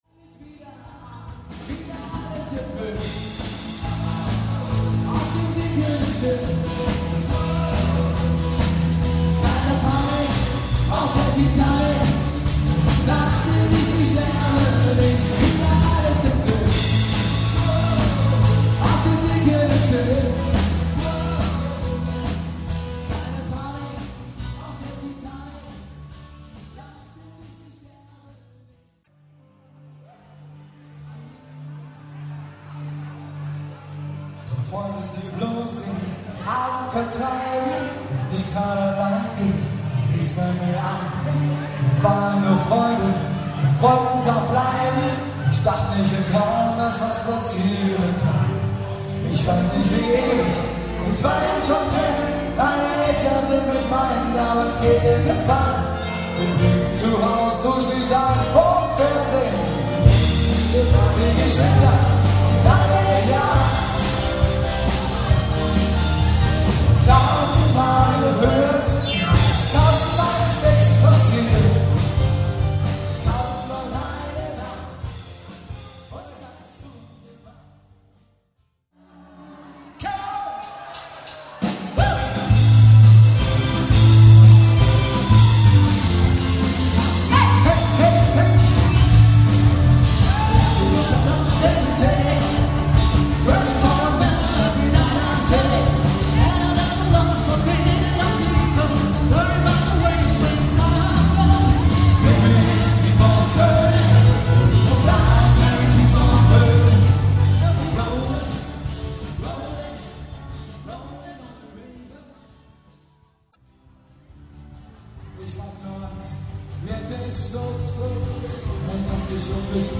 Live-Mitschnitte 2013